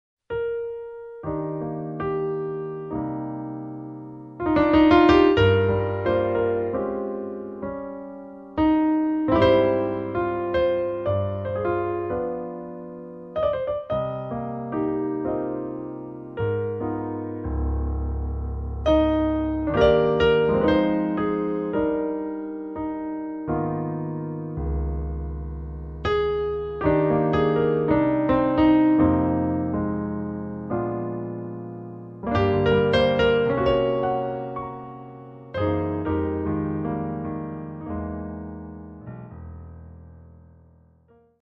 solo piano
Cool and classy lounge sounds